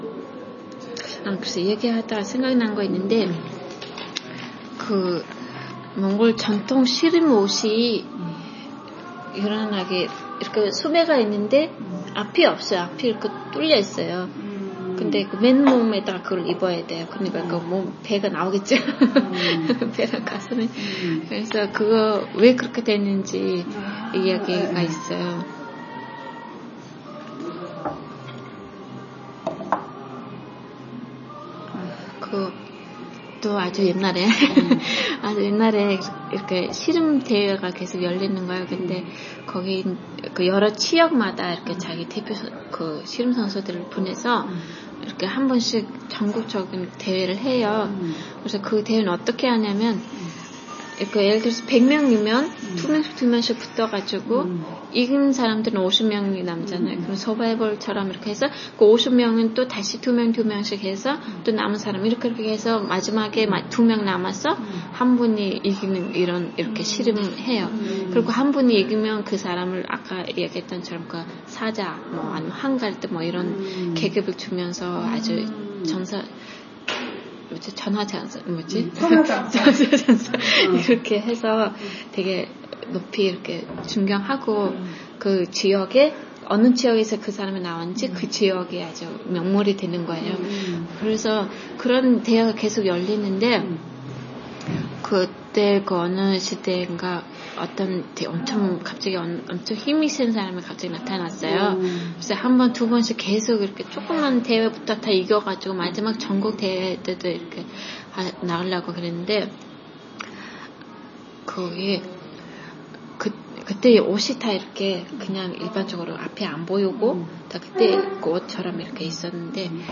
이야기분류전설
〈애기 있는 집에 멀쩡한 그릇이 없고 아들 있는 집에 멀쩡한 말잡는 막대기가 없다의 유래〉는 말에 대한 이야기를 하고 나서 제보자가 생각난 게 있다며 몽골 전통 씨름 옷에 대한 이야기를 해 주었다.